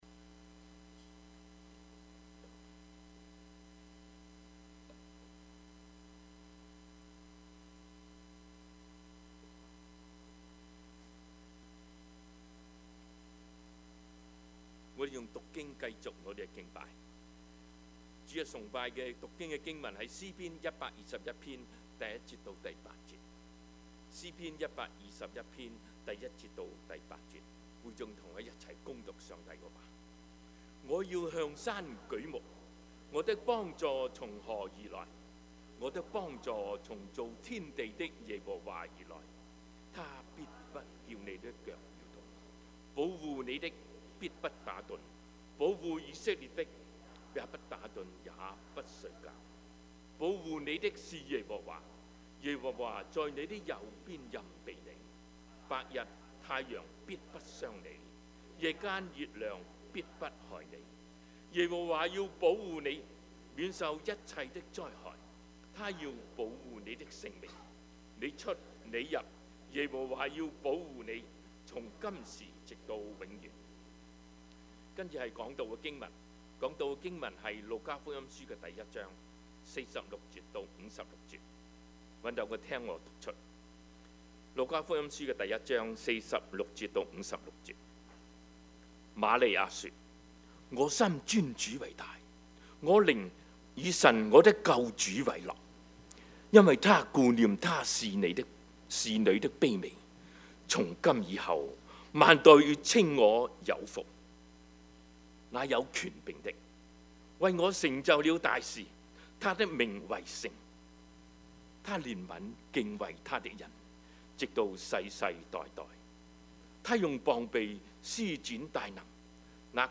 Service Type: 主日崇拜
Topics: 主日證道 « 凡事謝恩 仍是有望（福音主日） »